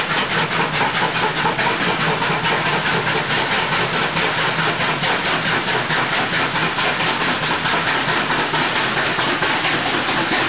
Victoria R766 at Wal Wal in 1994